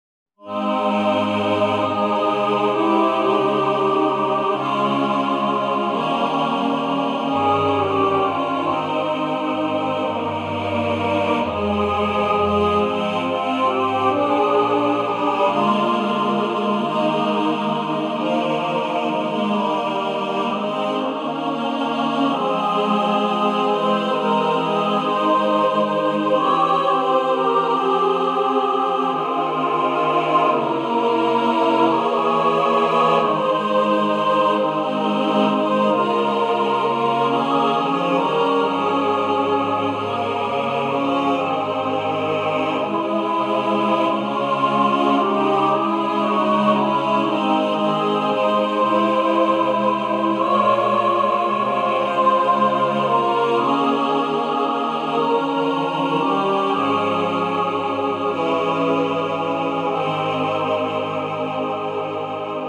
A gentle, beautiful, Christmas Hymn Lullaby.